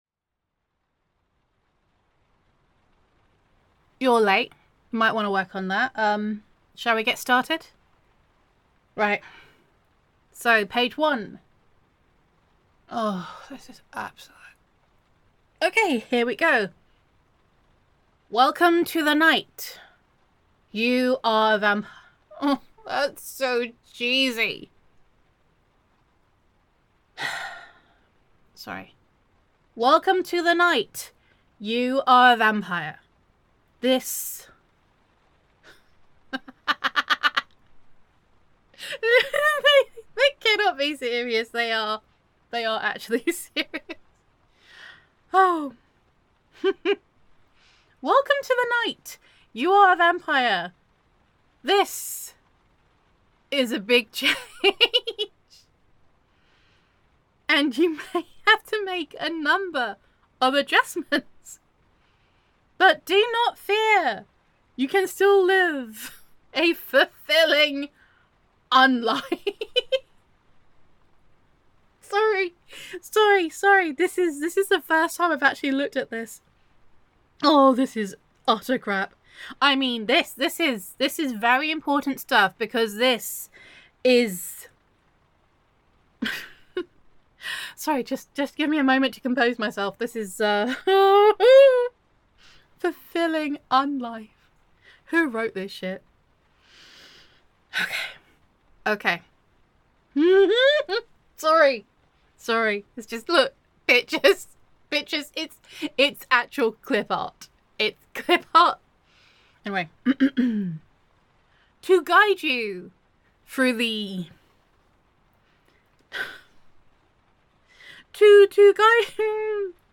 [F4A] Being a Vampire 101 [Vampire Roleplay]